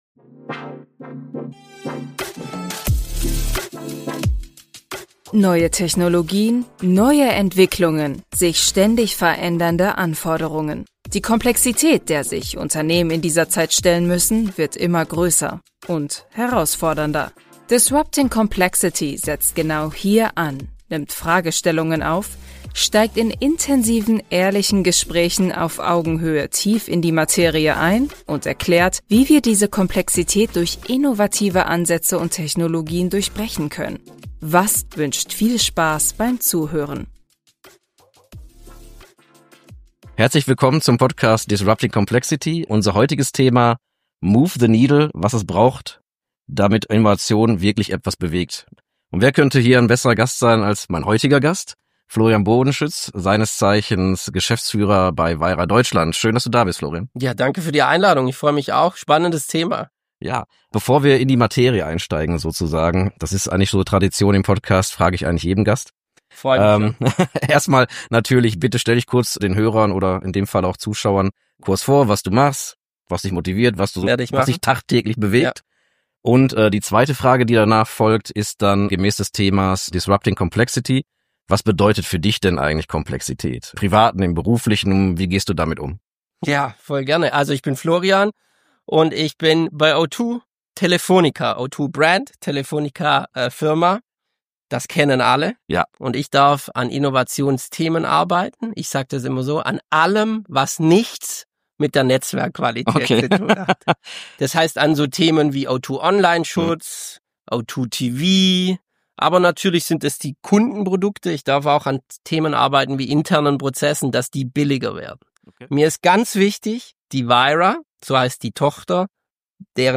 Play Rate Listened List Bookmark Get this podcast via API From The Podcast Herzlich willkommen bei "Disrupting Complexity", dem deutschsprachigen Podcast von VASS, der die Welt der digitalen Transformation auf verständliche Weise zugänglich macht.